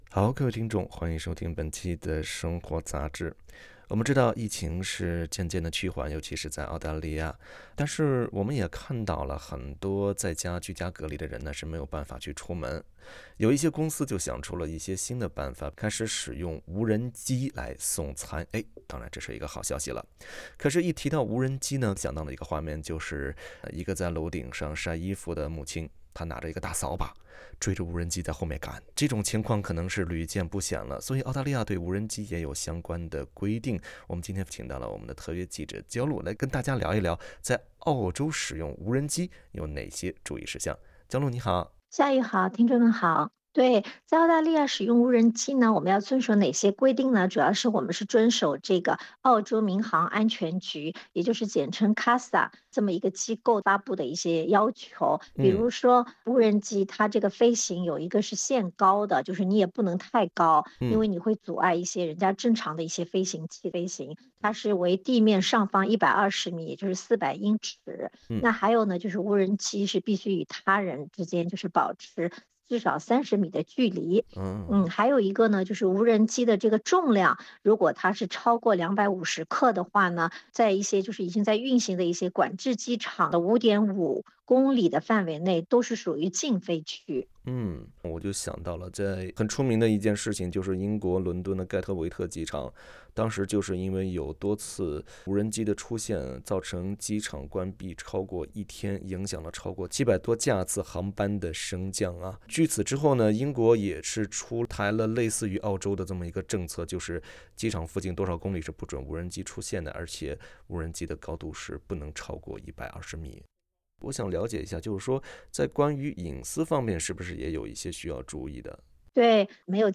无人机在疫情期间担负起了送货上门的职责，但是，澳大利亚对放飞无人机有具体的规定，违规行为可导致高达数万澳元的罚款。（欢迎点击图片音频，收听完整的采访。）